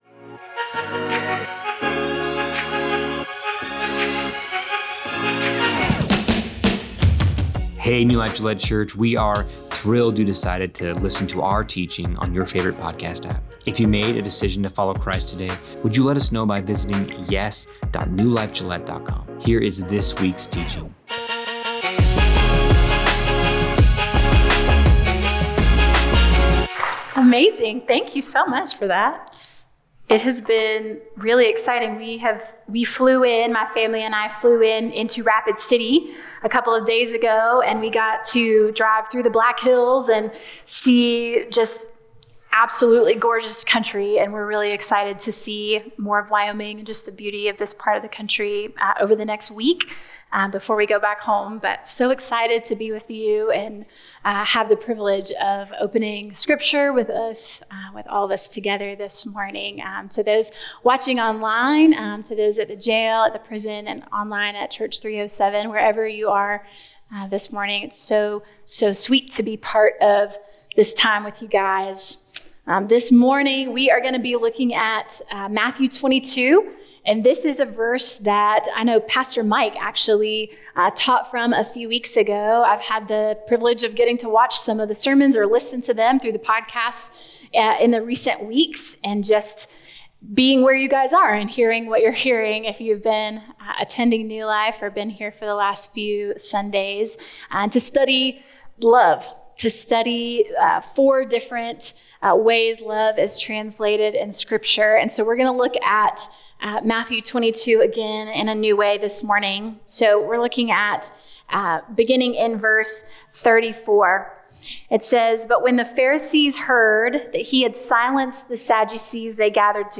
What does it mean to love God with all your heart, soul, mind, and strength? In this message